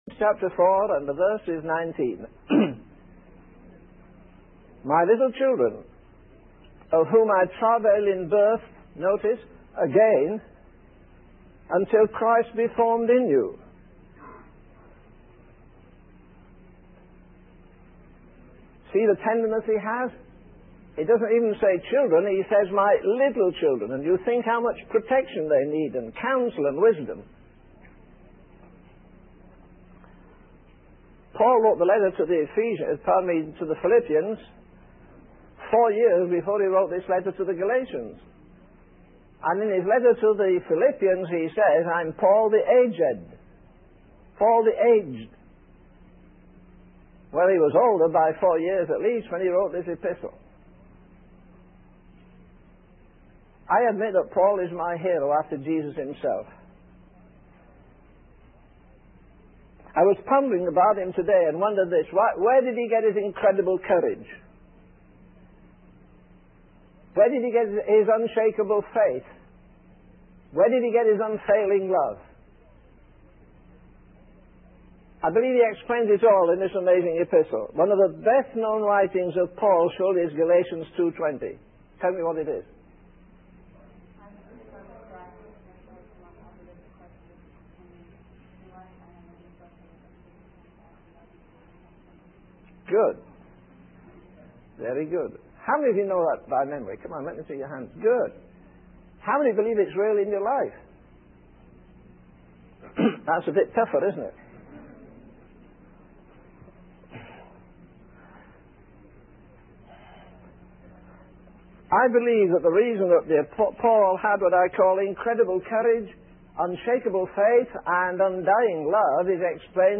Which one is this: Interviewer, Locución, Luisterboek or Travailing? Travailing